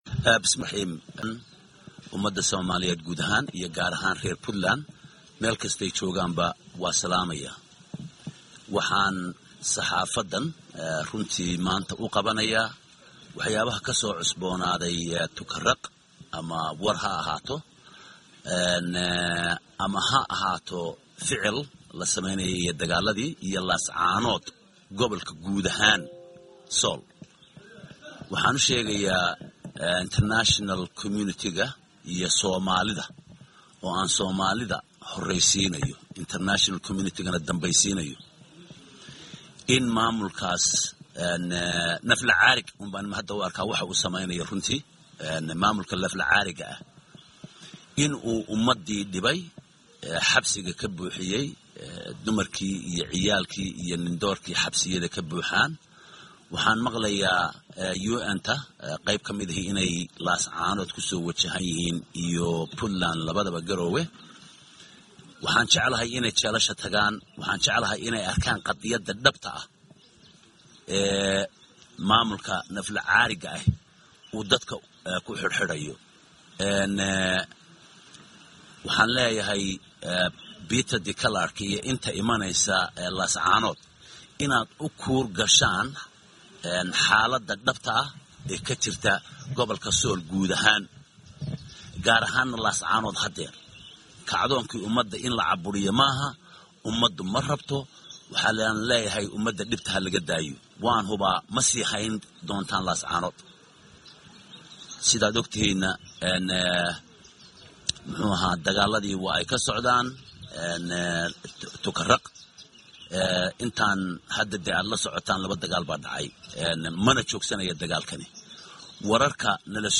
28 May 2018 (Puntlandes) Madaxweyne kuxigeenka Puntland Eng CabdiXakiim Xaaji Cumar Camey ayaa sheegay in uu soconayo dagaalka gobalka Sool hadda ka bilowday oo uusan istaagi doonin inta Taako ka mid ah dhulka Puntland ay ku jiraan ciidamada Somaliland.